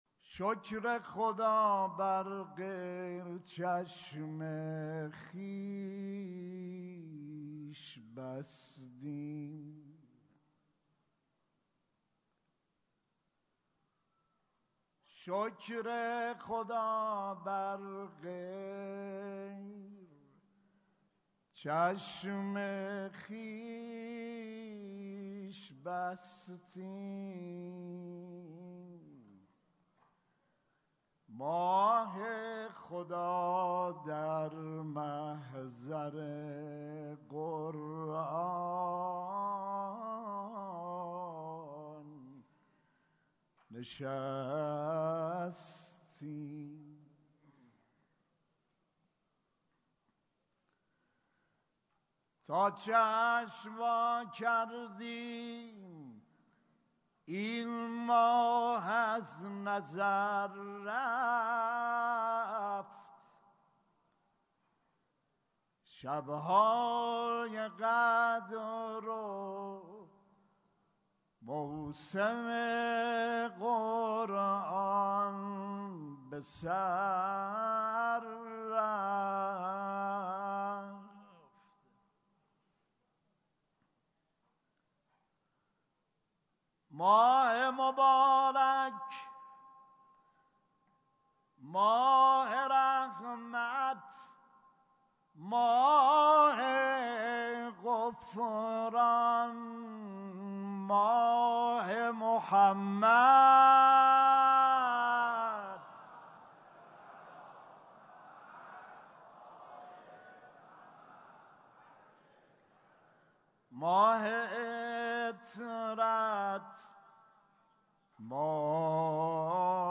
خطبه های نماز روز عید فطر
سخنرانی حجت الاسلام والمسلمین محمدجواد حاج علی اکبری